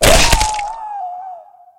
NeckSnap3.ogg